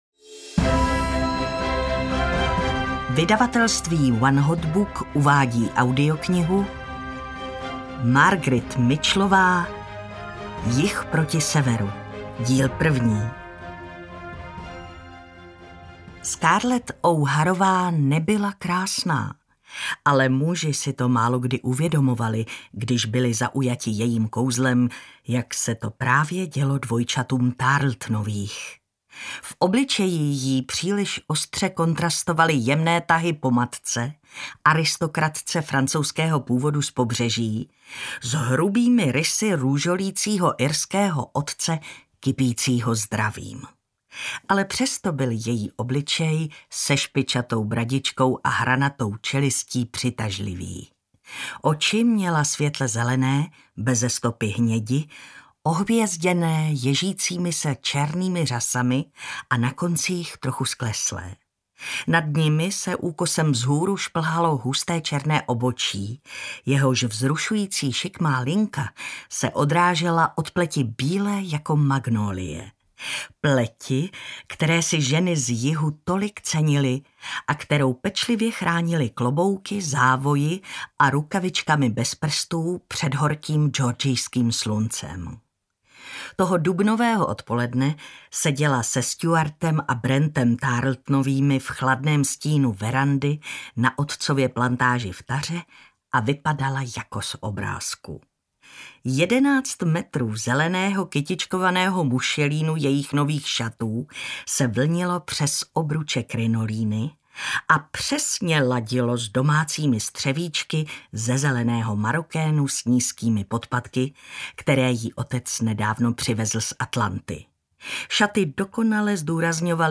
Jih proti Severu audiokniha
Ukázka z knihy